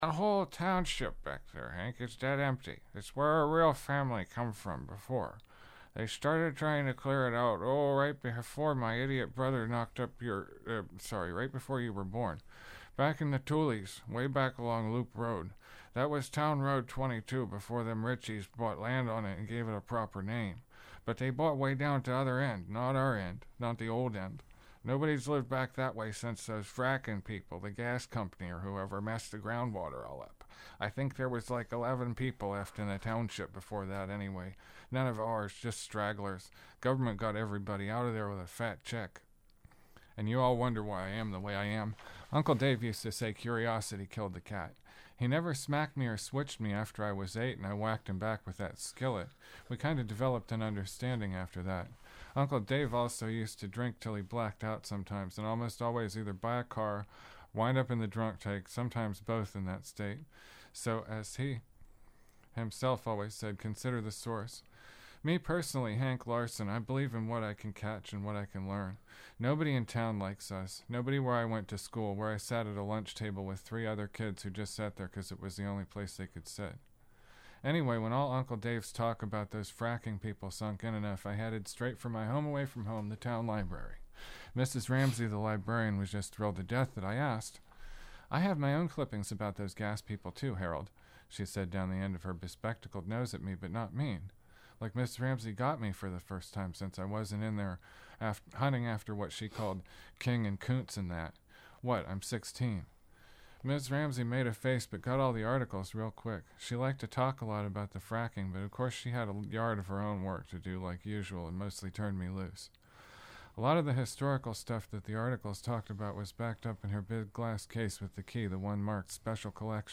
Poetry, spoken arts, prose